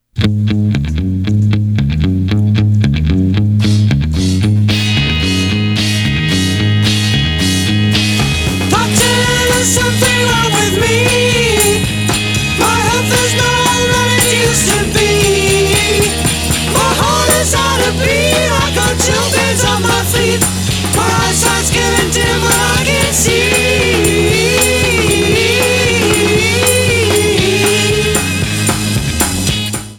Overall a clean mastering.